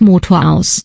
Diesen Logschen Schalter dann in Sprachausgabe verwendet um Sprachausgabe für Glühmeldung zu geben.